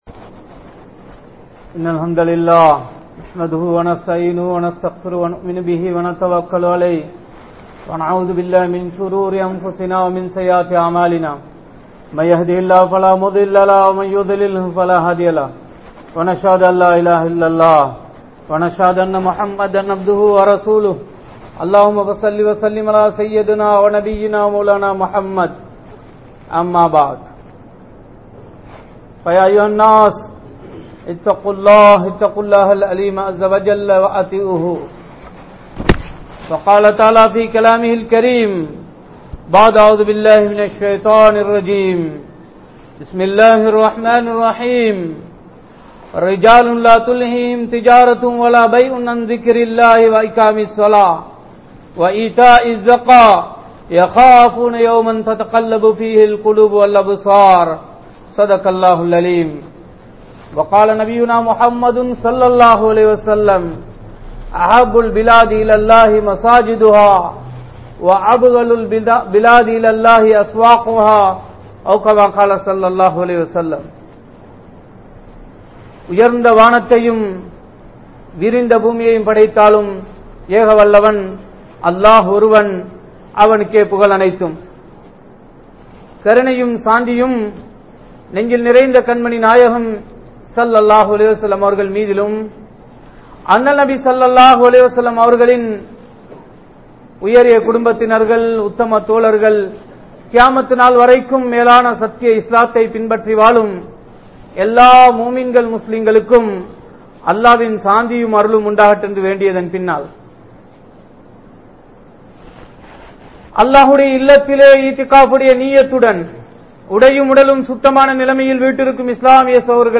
PalliVaasalukku Varuvoarin Sirappuhal (பள்ளிவாசலுக்கு வருவோரின் சிறப்புகள்) | Audio Bayans | All Ceylon Muslim Youth Community | Addalaichenai
Masjidhul Hakam Jumua Masjidh